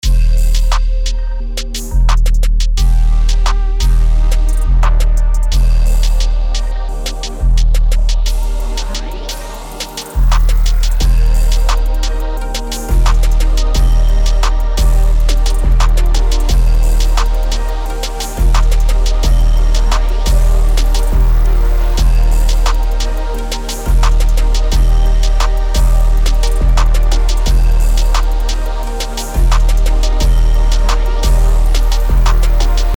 BPM: 175
Key: C minor